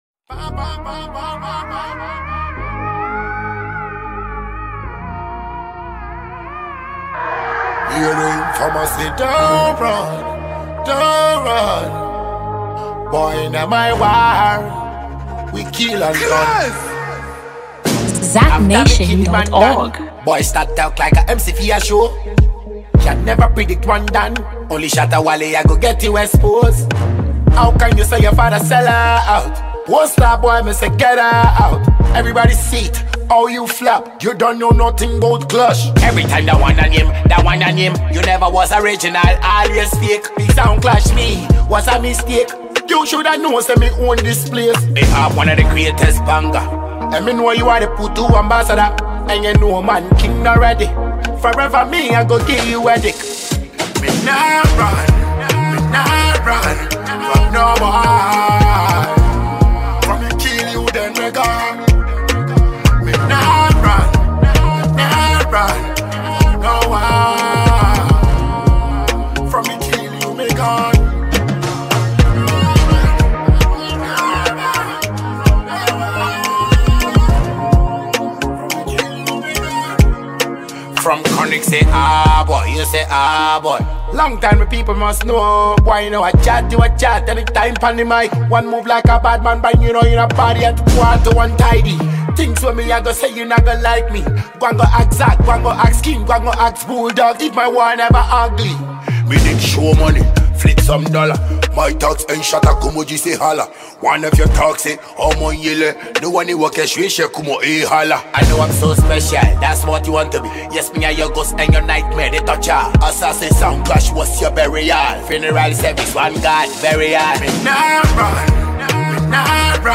Genre : Dancehall